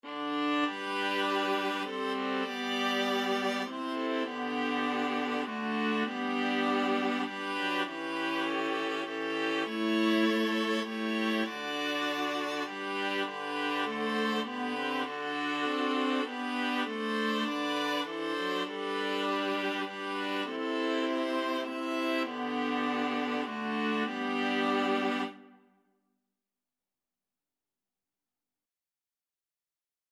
Viola 1Viola 2Viola 3Viola 4
3/4 (View more 3/4 Music)
Viola Quartet  (View more Intermediate Viola Quartet Music)
Classical (View more Classical Viola Quartet Music)